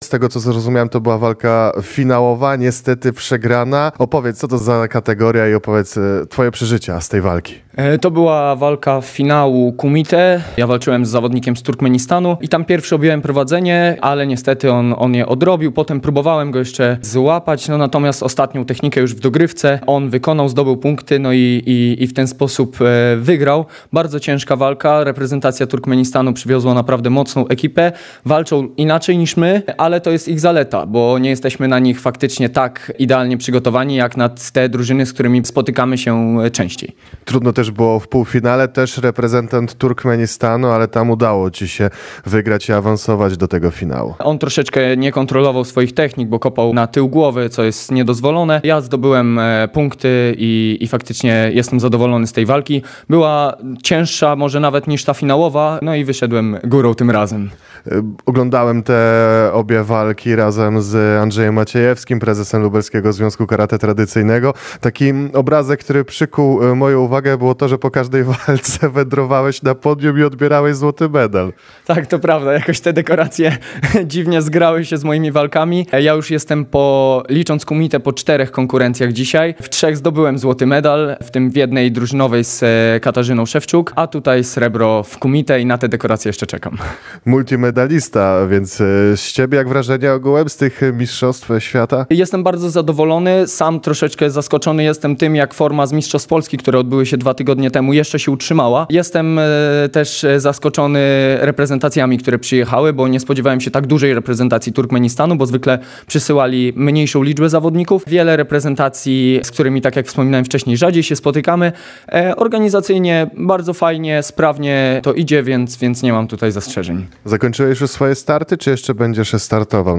Cała rozmowa w materiale dźwiękowym: Jutro zakończenie zawodów.